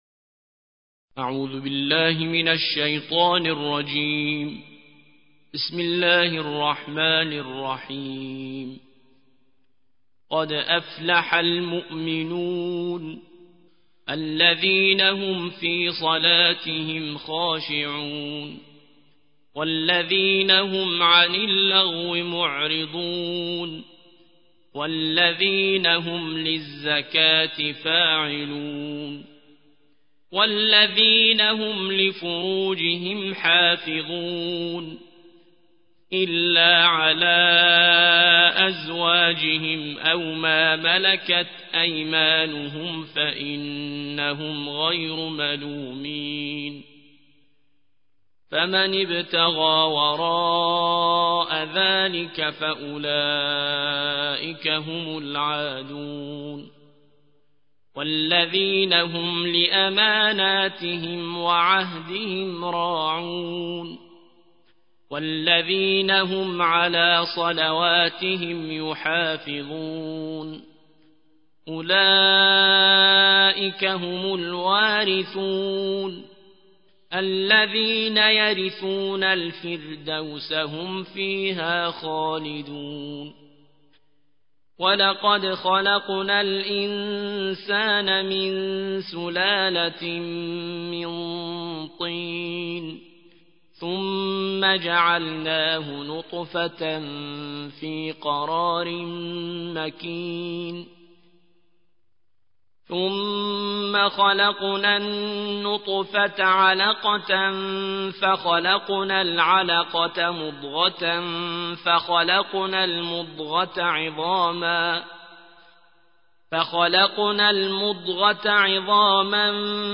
جزء خوانی قرآن کریم